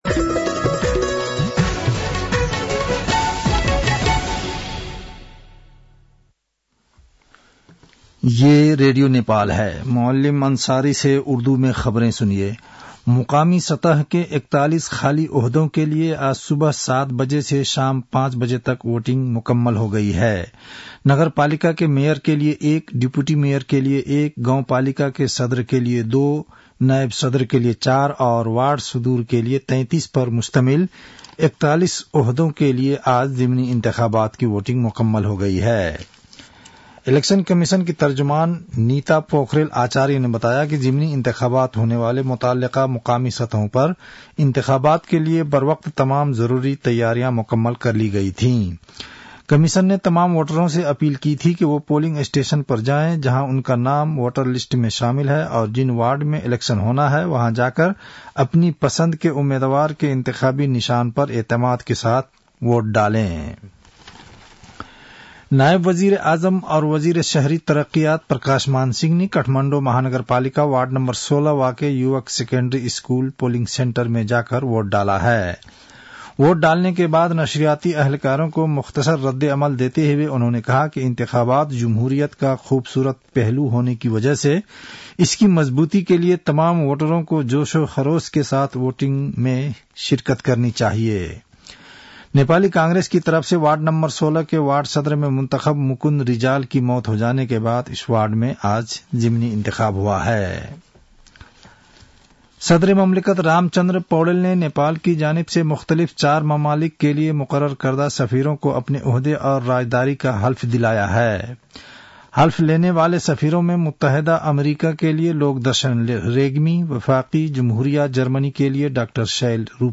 An online outlet of Nepal's national radio broadcaster
उर्दु भाषामा समाचार : १७ मंसिर , २०८१